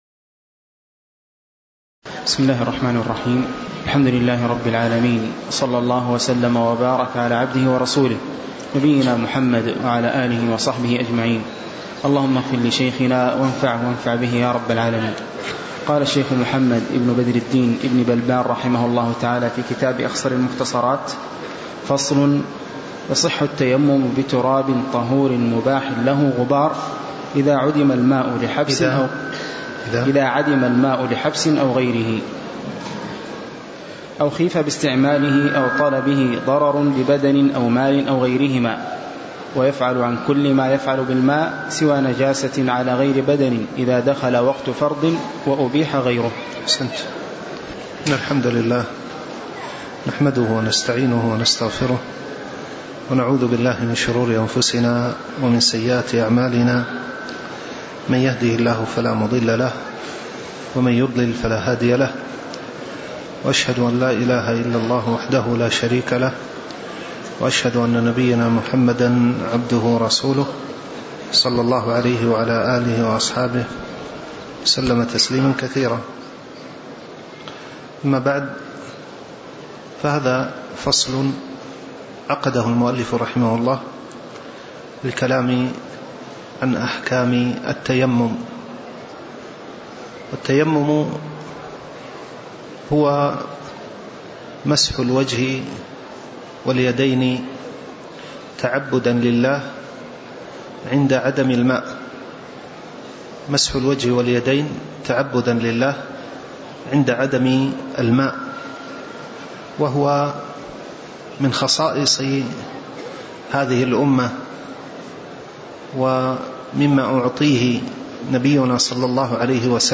تاريخ النشر ١١ ربيع الأول ١٤٣٩ هـ المكان: المسجد النبوي الشيخ